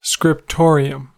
Ääntäminen
IPA : /skɹɪpˈtoː.ɹi.əm/
IPA : /skɹɪpˈtoɹ.i.əm/